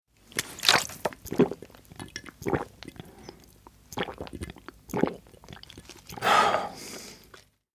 دانلود آهنگ آب 12 از افکت صوتی طبیعت و محیط
جلوه های صوتی
دانلود صدای آب 12 از ساعد نیوز با لینک مستقیم و کیفیت بالا